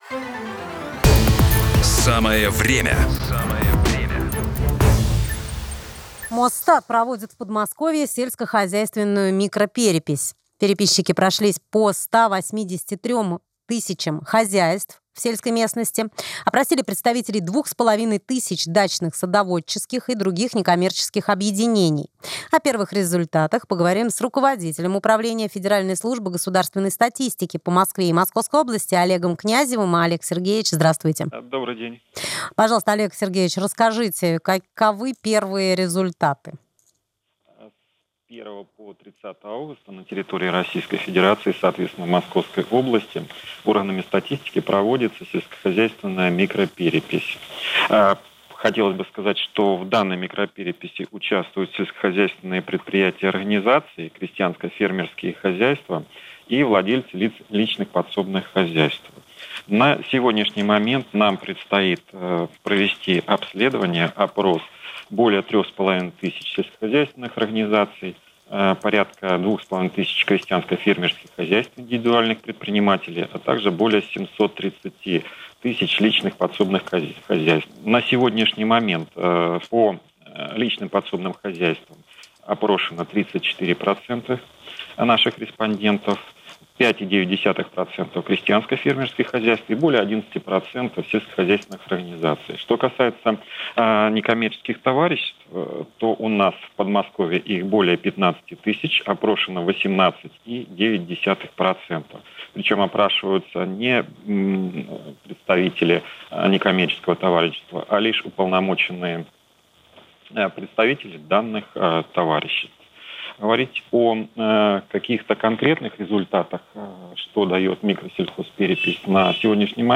11 августа 2021 года руководитель Мосстата Князев Олег Сергеевич в эфире радиостанции  «РАДИО 1» рассказал о ходе сельскохозяйственной микропереписи, которая стартовала 1 августа.
Интервью руководителя Мосстата Князева Олега Сергеевича